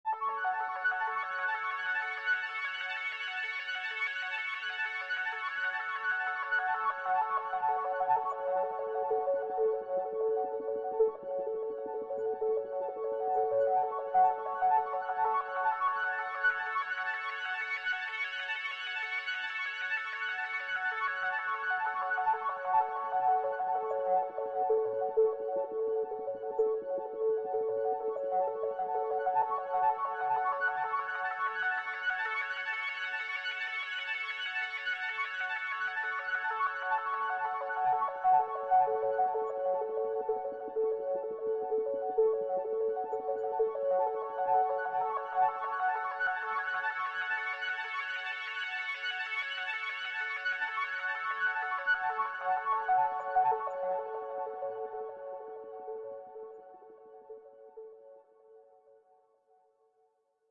Scary And Wondering Sound Effect Download: Instant Soundboard Button